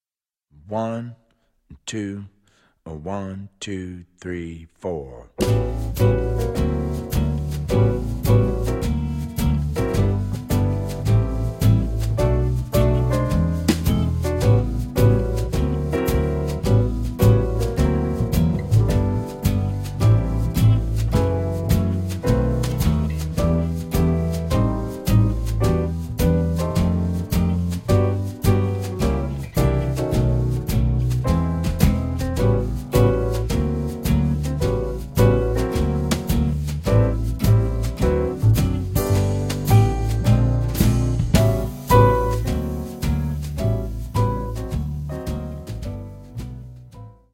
Besetzung: Instrumentalnoten für Trompete